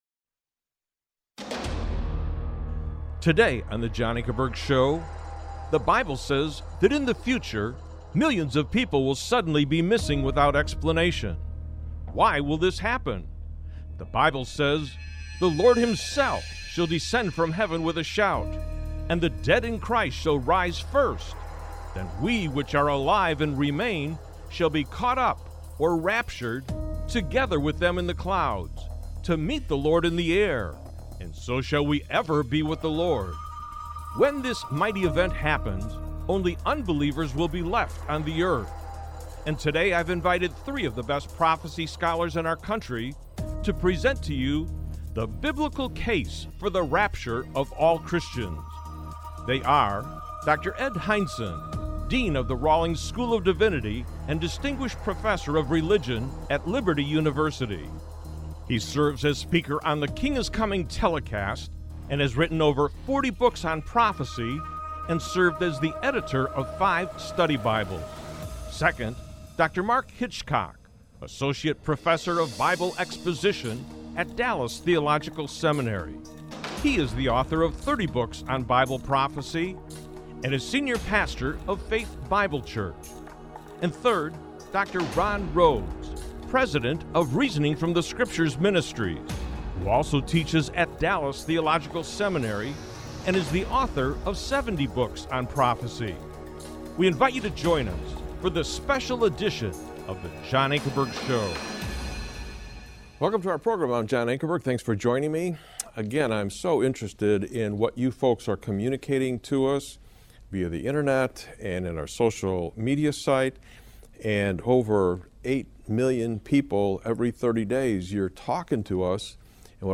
The formats of choice for this apologetics ministry are informal debates between representatives of differing belief systems, and documentary-styled presentations on major issues in society to which the historic Christian faith has something of consequence to say.